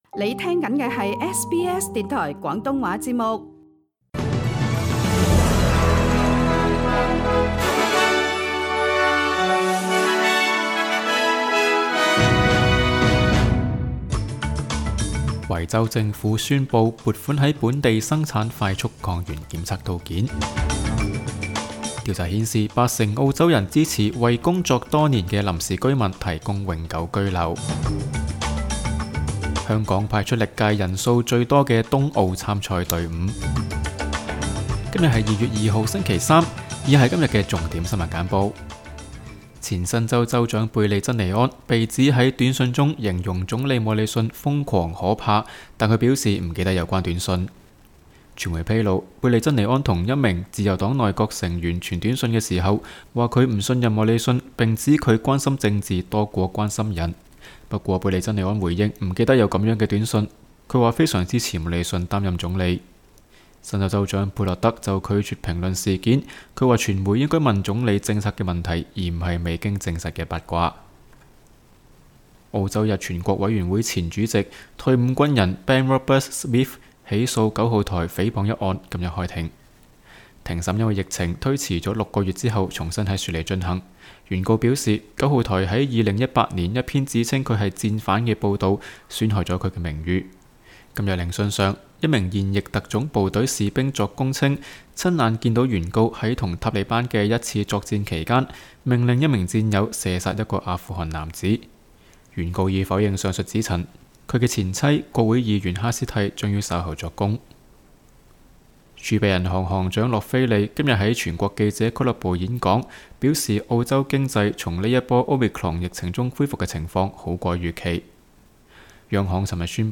SBS 新闻简报（2月2日）